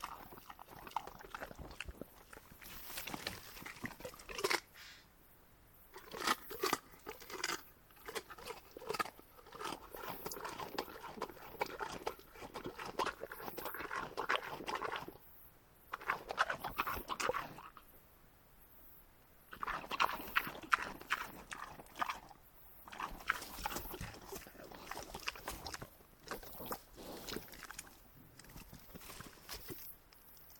Cette création sans commentaire ni musique accompagnera les auditeurs durant 72 minutes à l'écoute des plus belles découvertes sonores au fil d'une année dans cette magnifique région qu'est le Diois en Drôme.
4 -- Naturophonie automnale